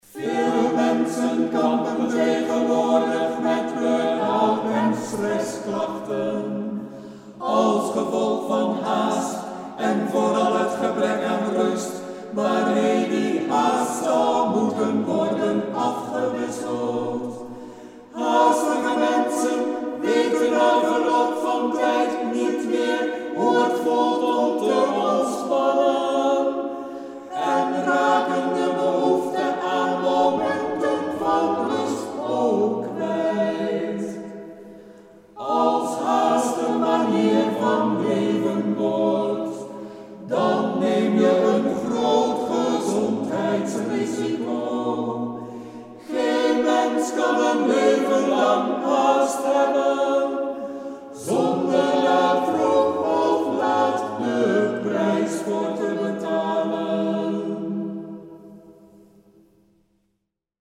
In kwartetjes gezongen op de wijze van vierstemmige Engelse ‘chants’: